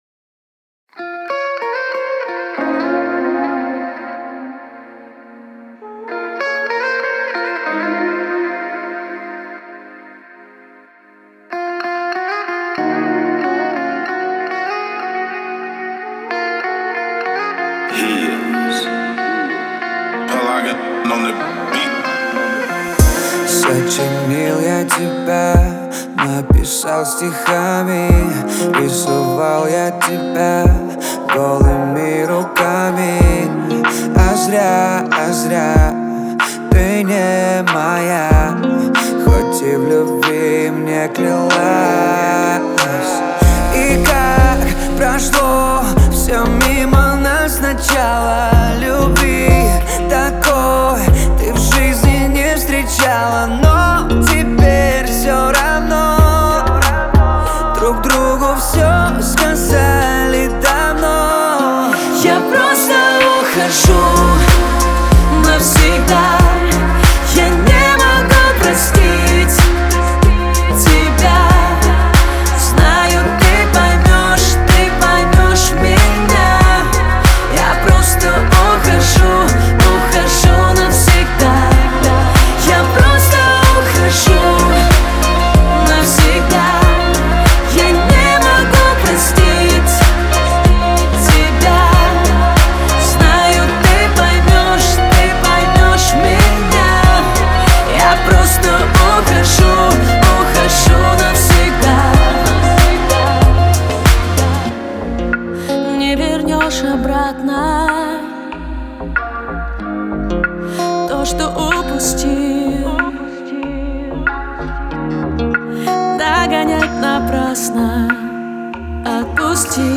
Трек размещён в разделе Русские песни / Поп / 2022.